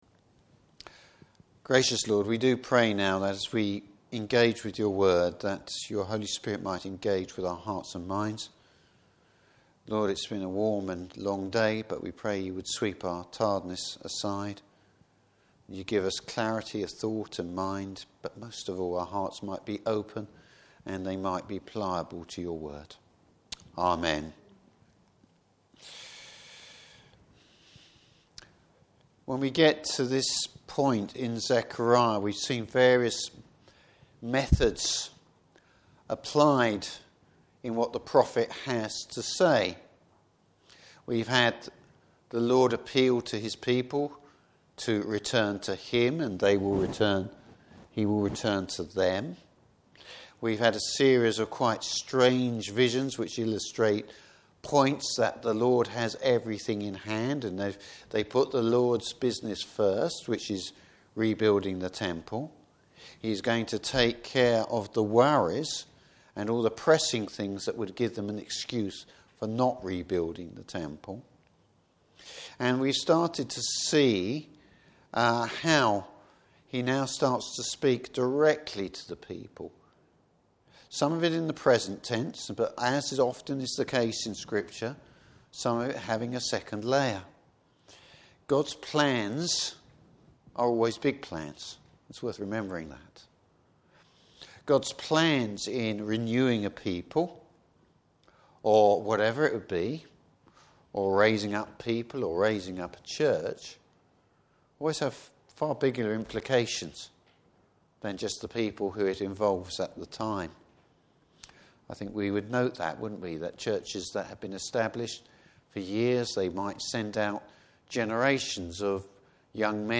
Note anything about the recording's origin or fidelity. Service Type: Evening Service God’s continual and future care for His people.